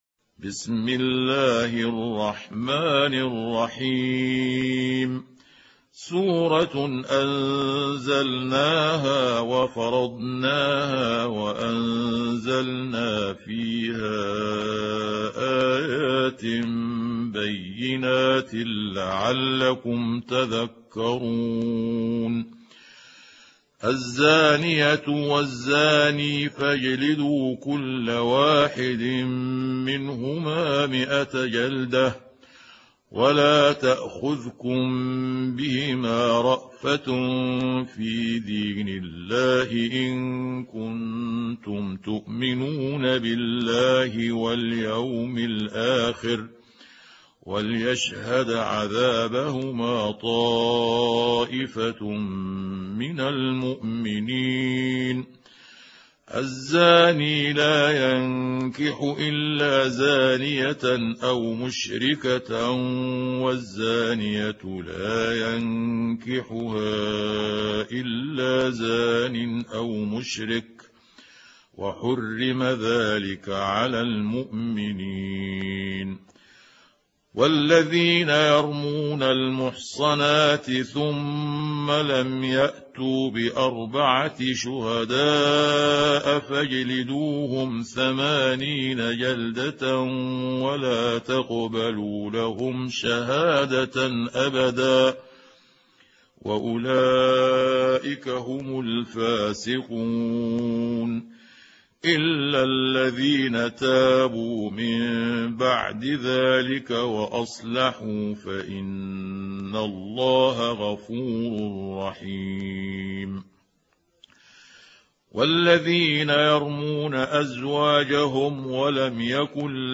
سورة النور | القارئ محمود عبد الحكم